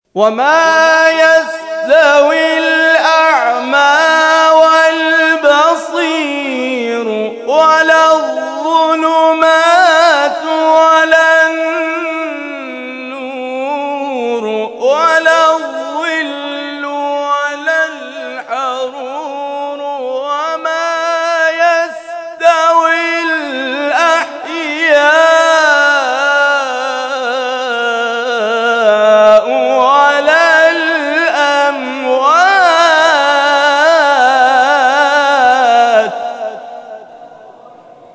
در ادامه قطعات تلاوت این کرسی‌های تلاوت ارائه می‌شود.